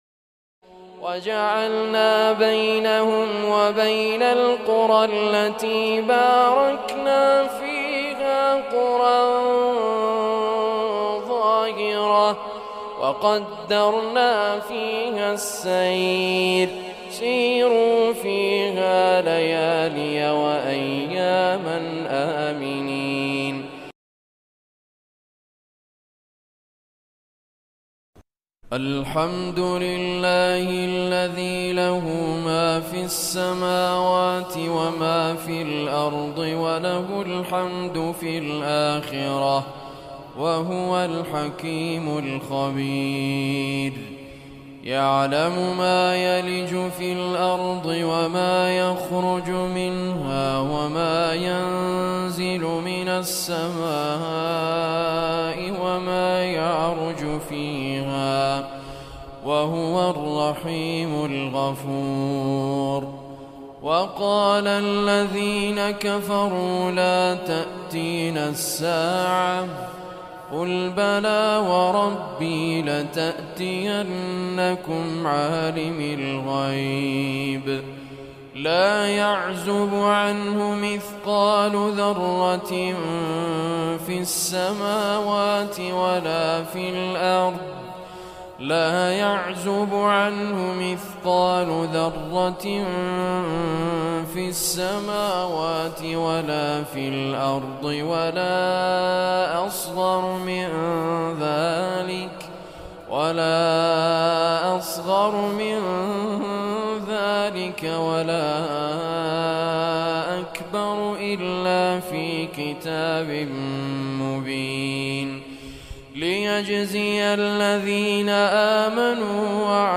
Surah Saba, listen or play online mp3 tilawat / recitation in the beautiful voice of Sheikh Muhammad Raad Al Kurdi.